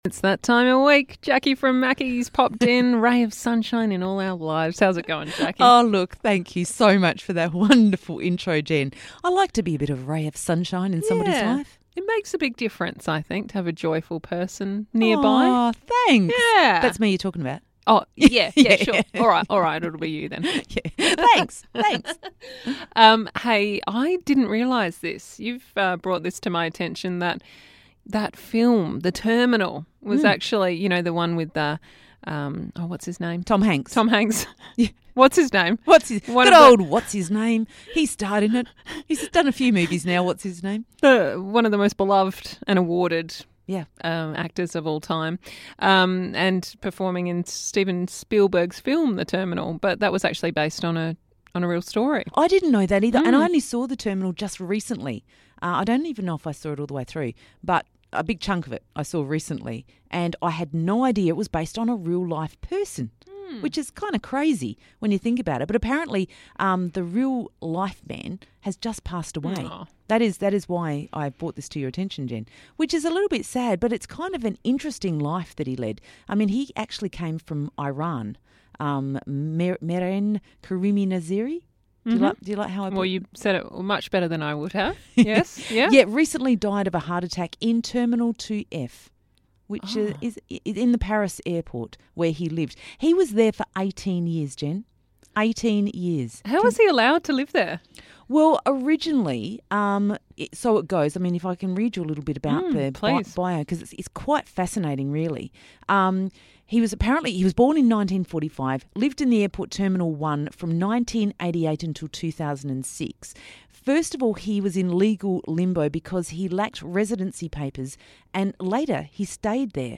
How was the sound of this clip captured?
talk in studio about the story and reflect on the times they've been stuck in airports (for not quite as long, let's be honest).